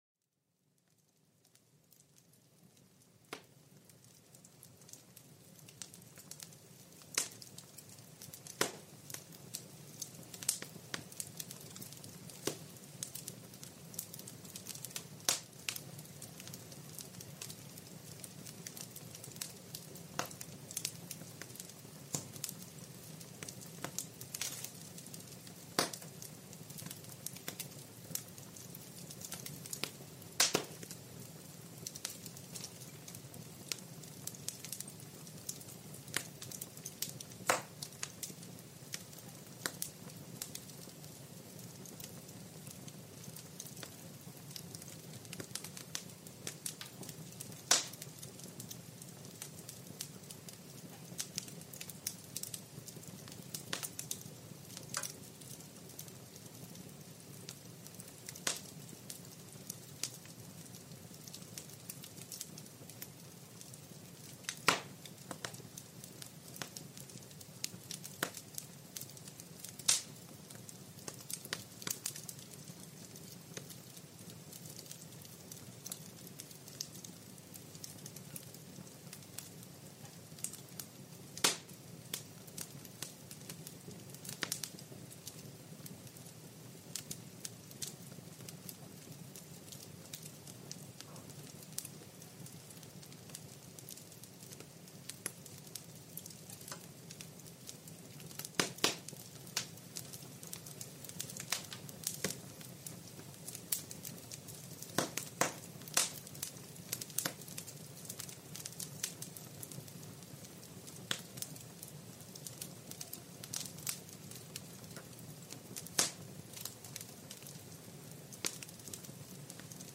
Plenitud junto al fuego: el crepitar tranquilizador para calmar la mente